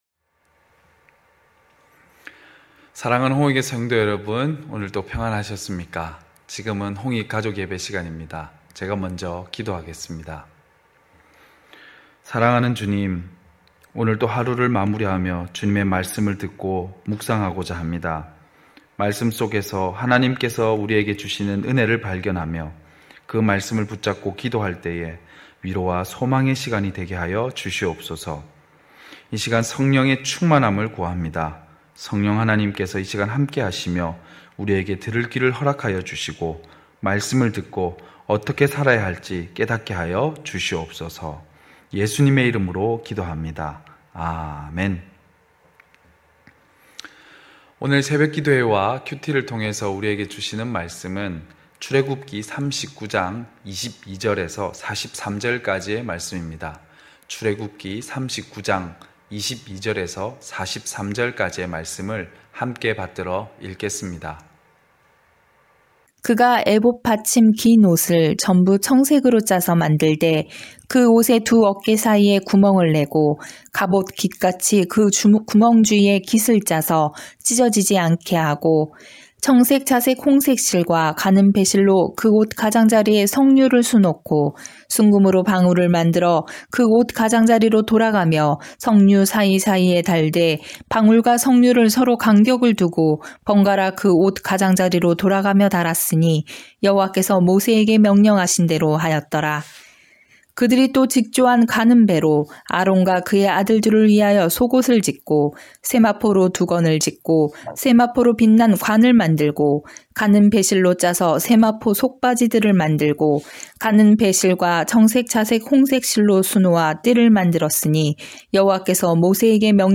9시홍익가족예배(10월29일).mp3